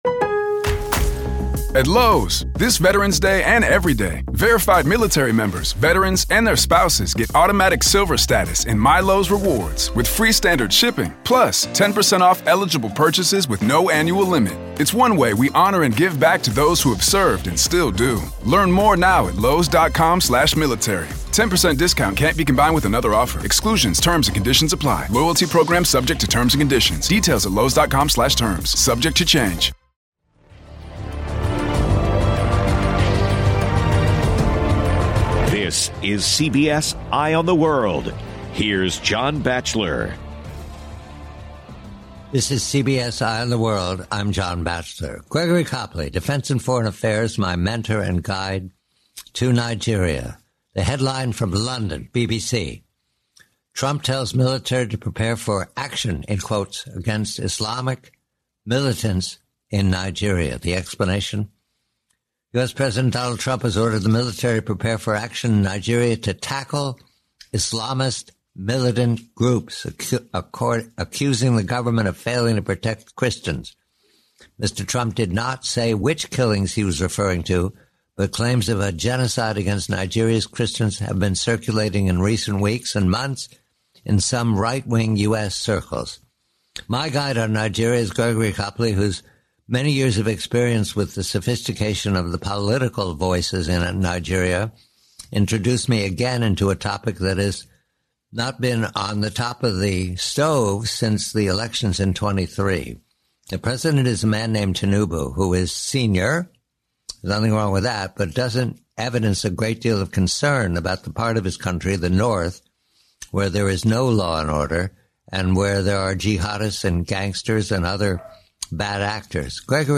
Discussion shifts to the overwhelming US military buildup near Venezuela, which might force Maduro's departure by damaging his credibility, possibly via anti-narcotics action. The interview concludes by analyzing the anticlimactic Trump-Xi meeting, attributing the lack of confrontation to Xi Jinping's significantly weakened position due to China's shattered economy and internal power struggles. 1911 NIGERIA